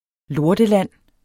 Udtale [ ˈloɐ̯dəˌlanˀ ]